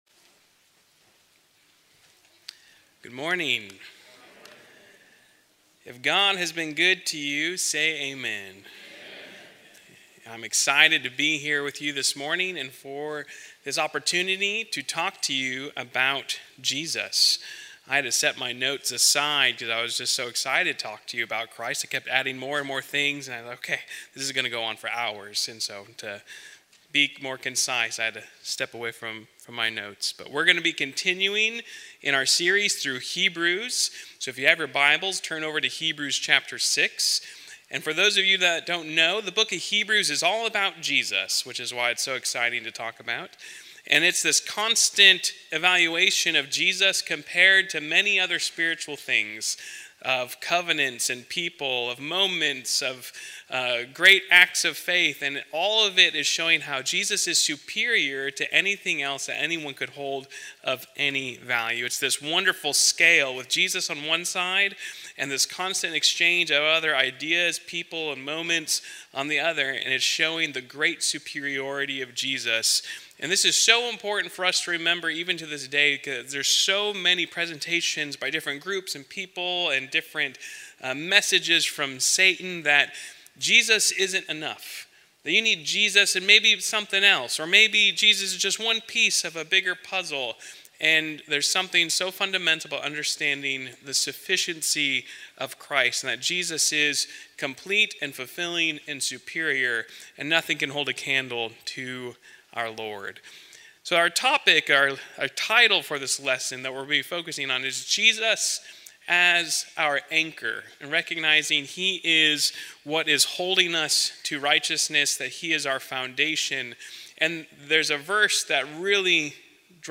Home Resources Sermons